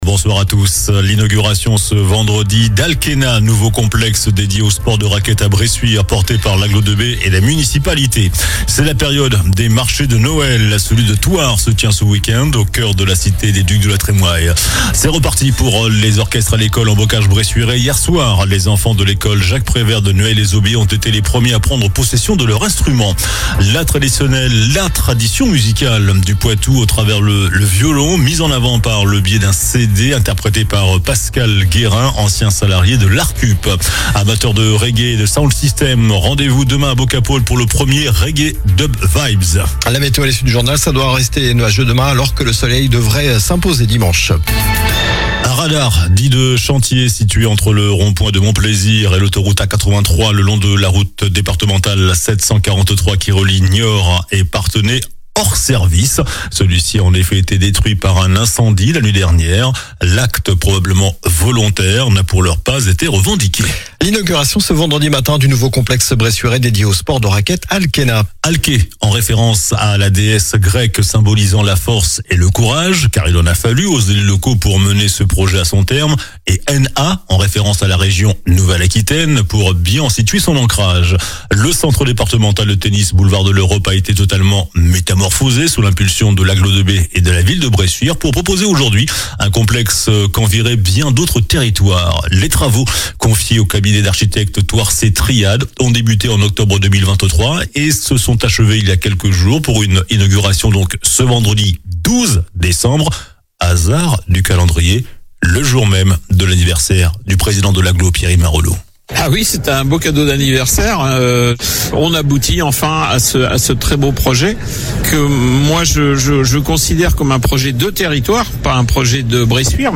JOURNAL DU VENDREDI 12 DECEMBRE ( SOIR )